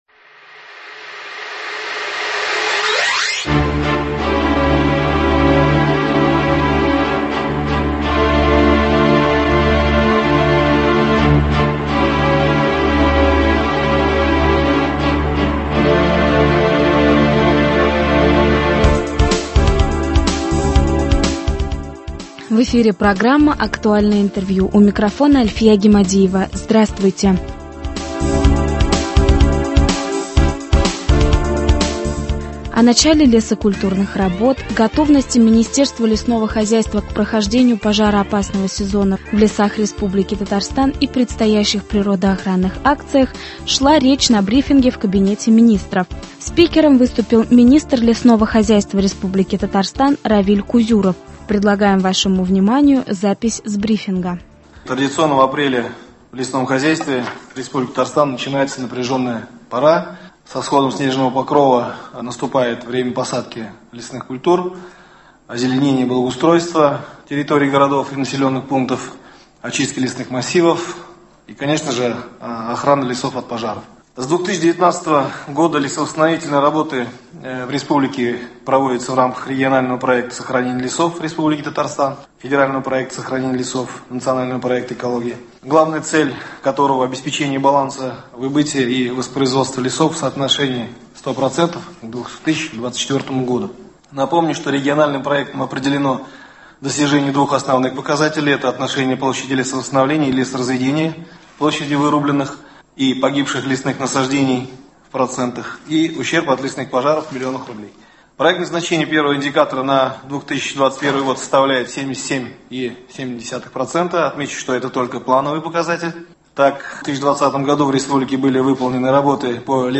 Актуальное интервью (21.04.21) | Вести Татарстан
О начале лесокультурных работ, готовности к прохождению пожароопасного сезона в лесах Республики Татарстан и предстоящих природоохранных акциях рассказал на брифинге в Кабмине Равиль Кузюров — министр лесного хозяйства Республики Татарстан.